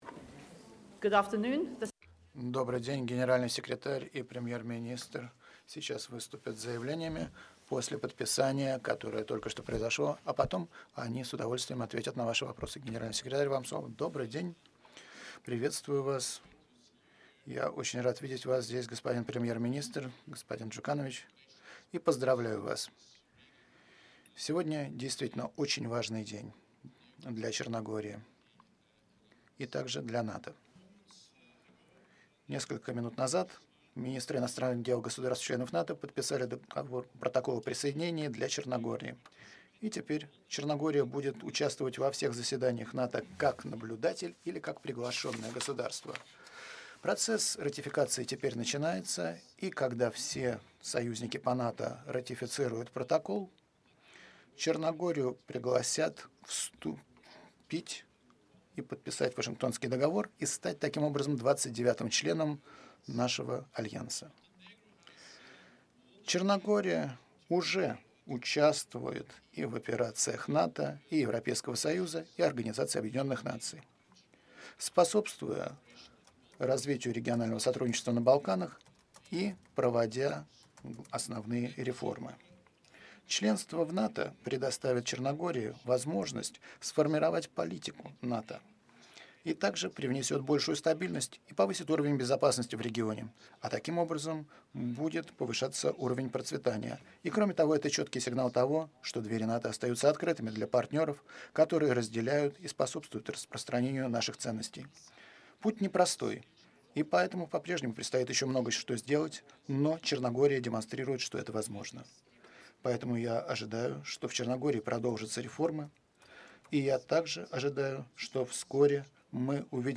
Joint press conference
by NATO Secretary General Jens Stoltenberg and Prime Minister of Montenegro Milo Đukanović